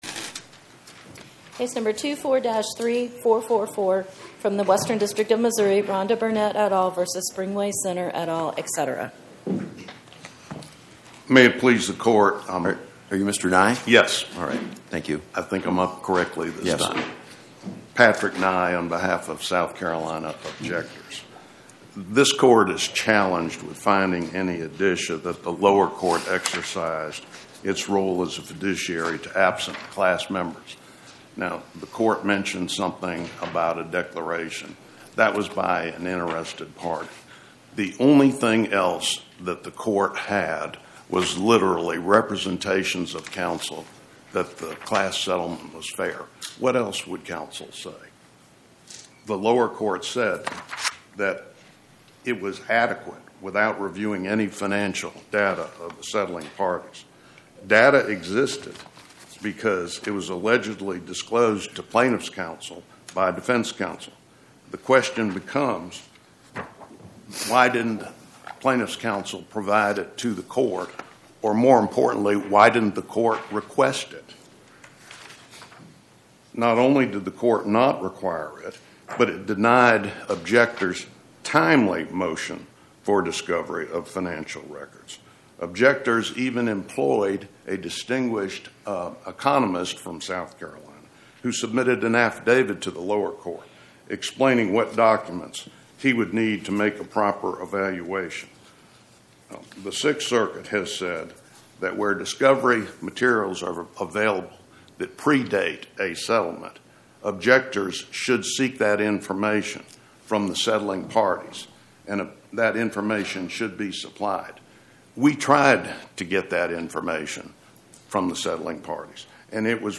Oral argument argued before the Eighth Circuit U.S. Court of Appeals on or about 01/14/2026